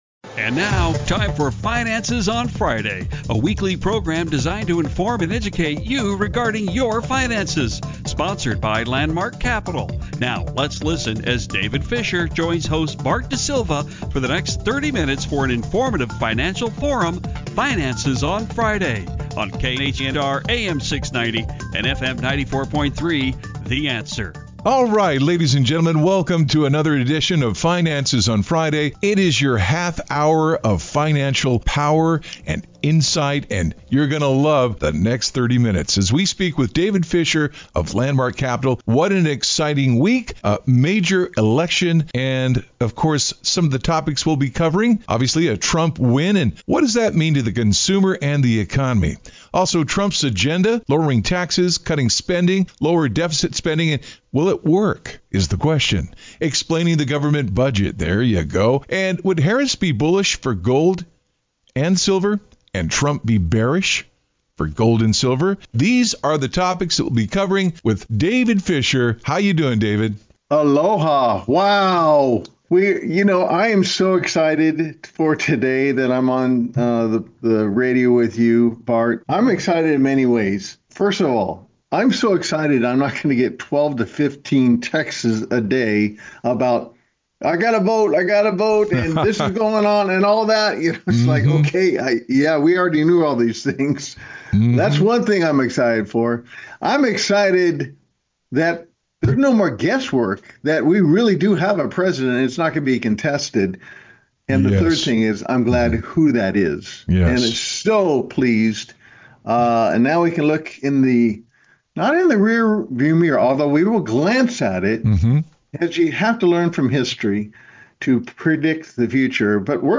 radio talk show